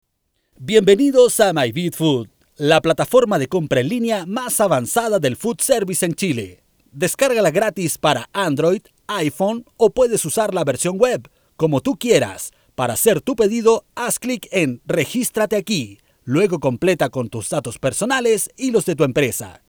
chilenisch
Sprechprobe: Sonstiges (Muttersprache):